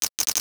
NOTIFICATION_Rattle_09_mono.wav